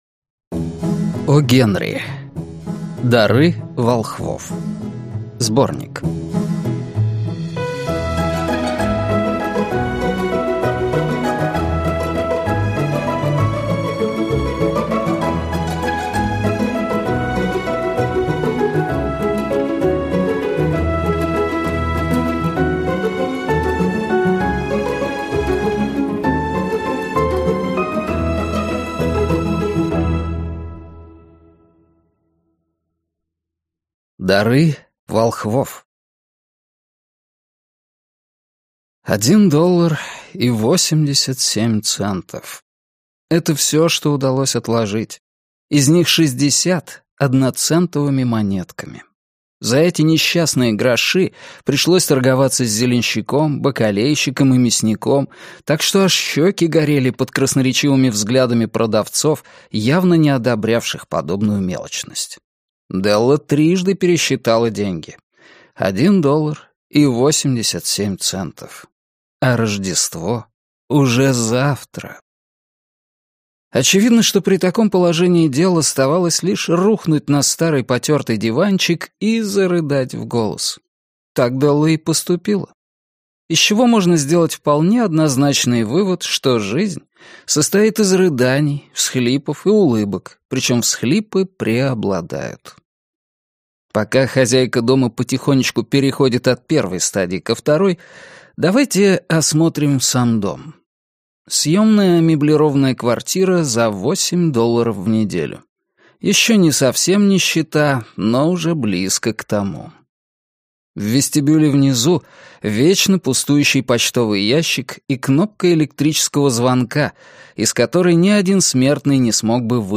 Аудиокнига Дары волхвов | Библиотека аудиокниг